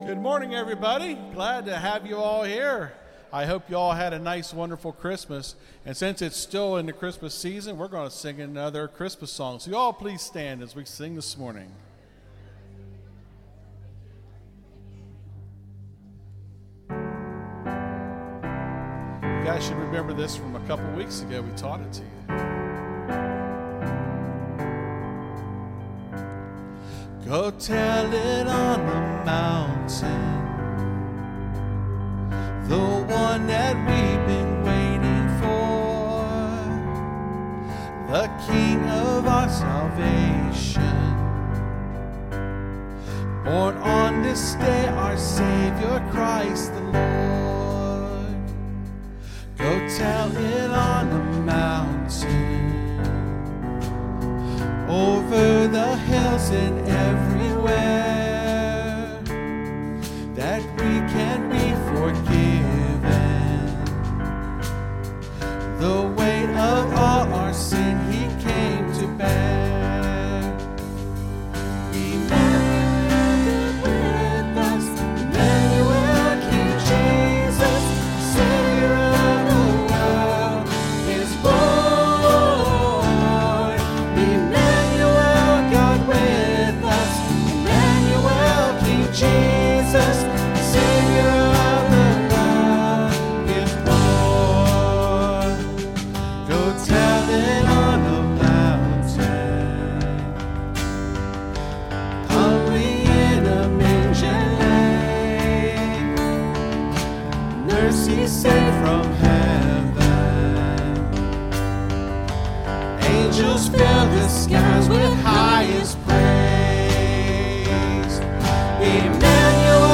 (Sermon starts at 28:35 in the recording).